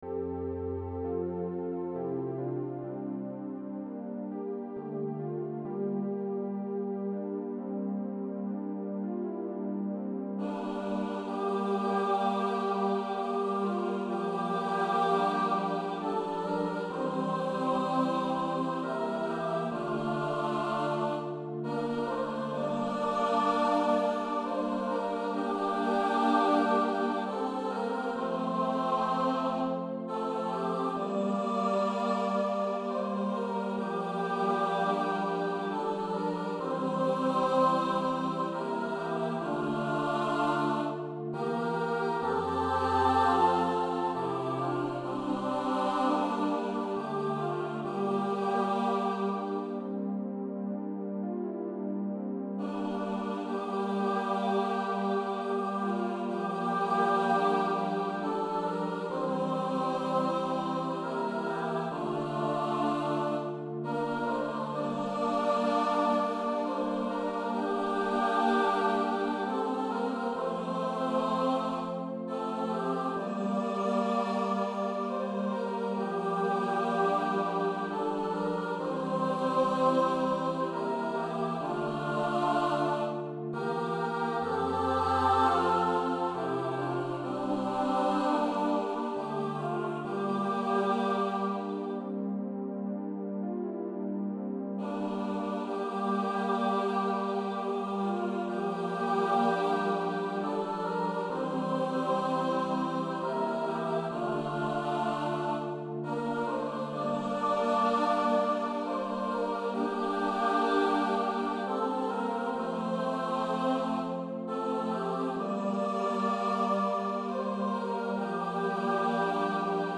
Voicing/Instrumentation: SAB , Organ/Organ Accompaniment